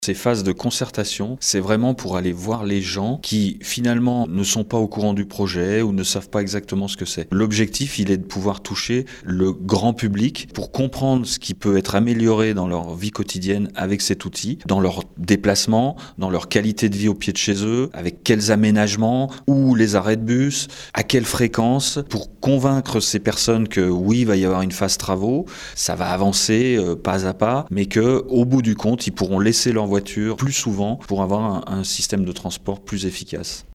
Ces phases de concertation sont très importantes comme l’explique Didier Sarda, il est Vice-président du grand Annecy en charge des mobilités.